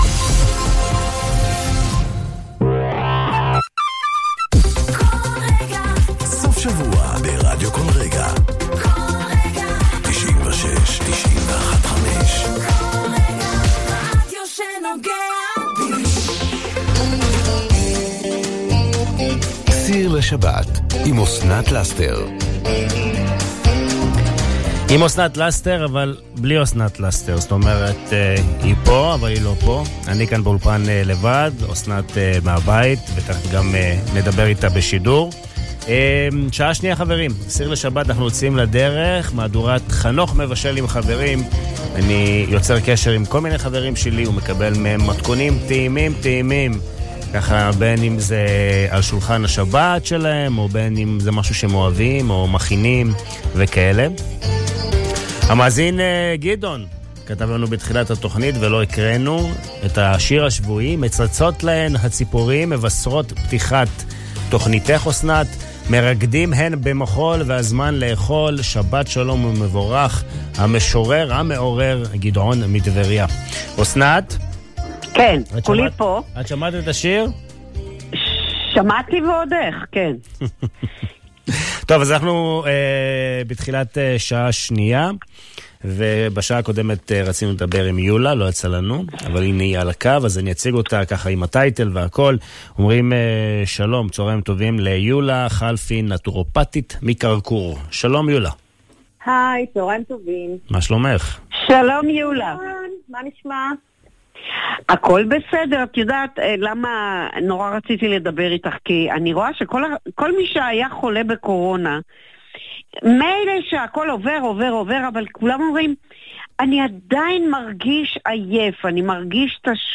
שמעו אותי ברדיו